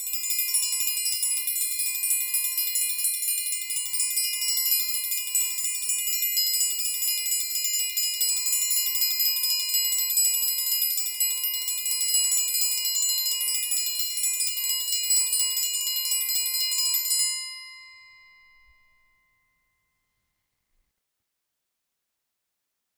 Triangle6-Roll_v2_rr1_Sum.wav